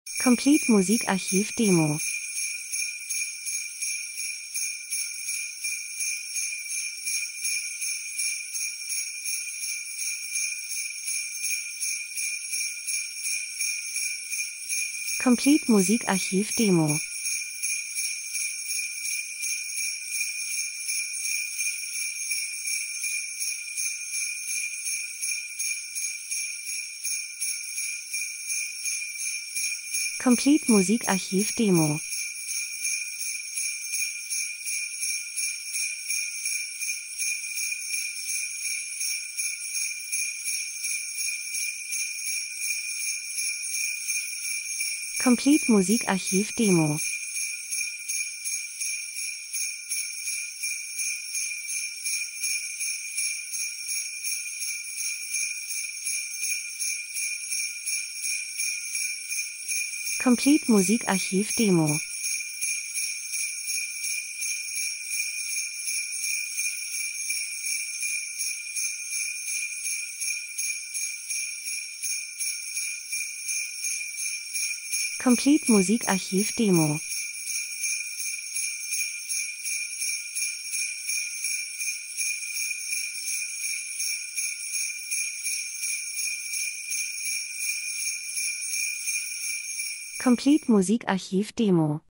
Winter - Schlitten Glöckchen Schlitten 01:31